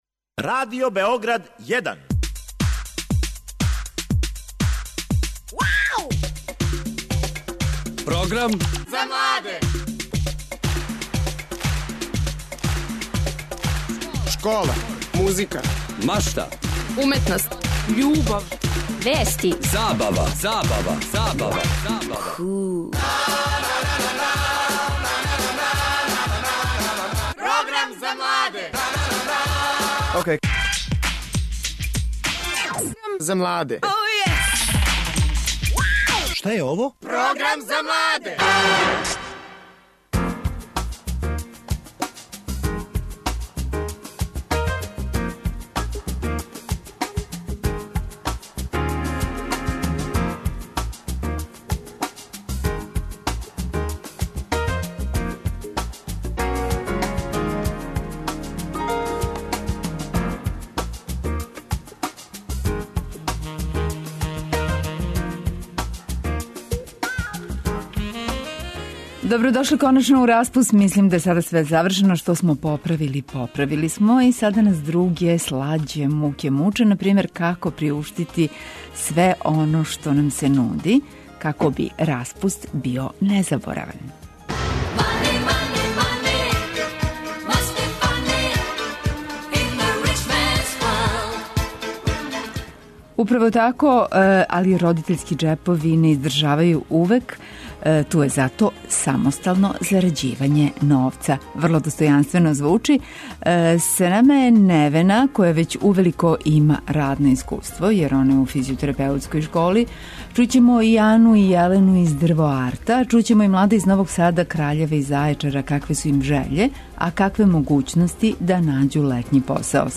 Чућете и мишљења наших гостију, младих који већ раде, а имаћемо и укључења из градова широм Србије - покушаћемо да сазнамо какве су могућности запослења током лета.